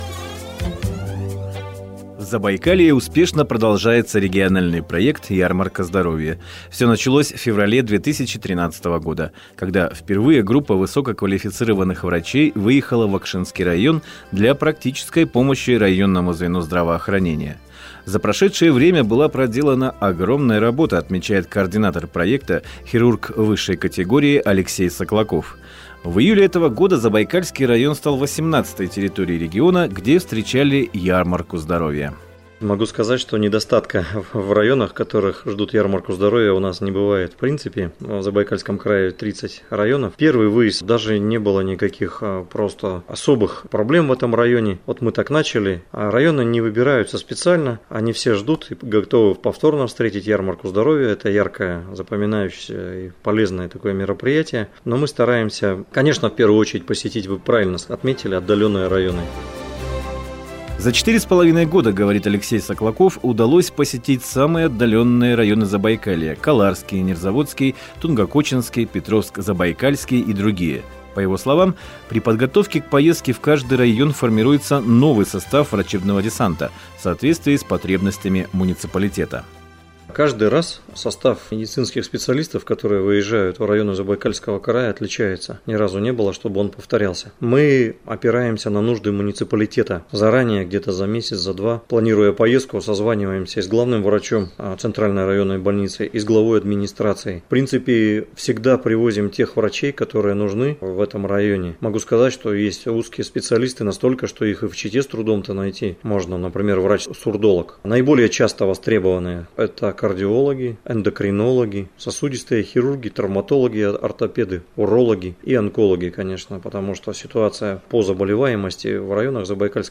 Заместитель председателя комитета по социальной политике Алексей Саклаков – о проекте "Ярмарка здоровья". Интервью "Радио России"